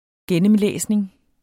Udtale [ -ˌlεˀsneŋ ]